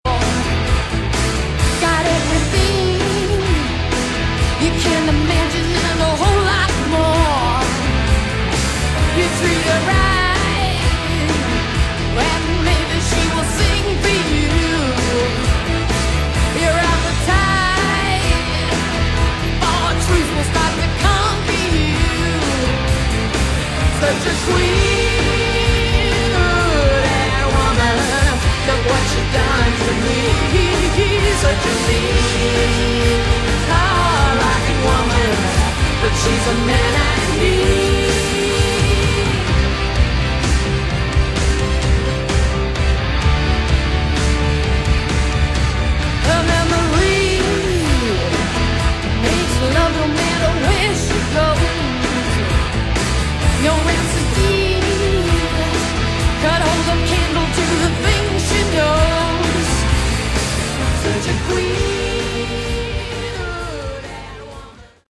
Category: Hard Rock
vocals
guitar
drums
keyboards, bass